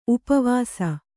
♪ upa vāsa